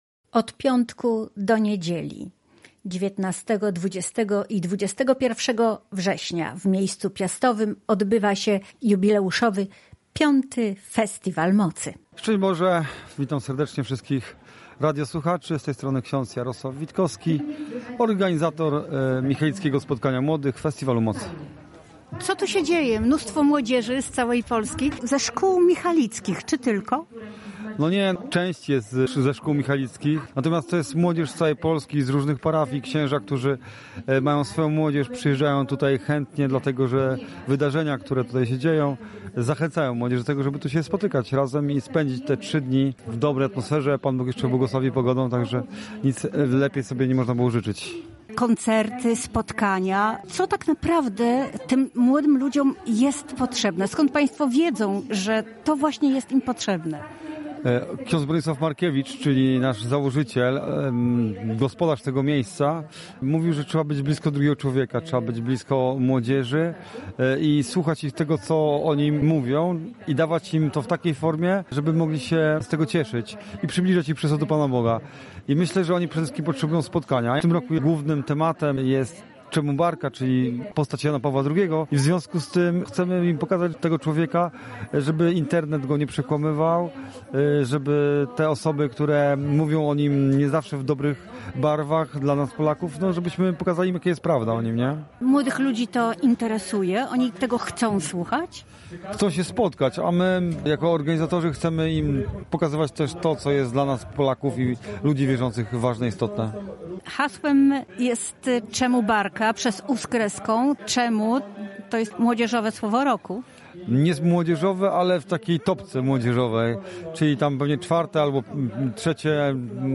Organizatorzy michalickiego spotkania młodych chcą oddać hołd św. Janowi Pawłowi II oraz przybliżyć jego postać młodym ludziom. Rozmawia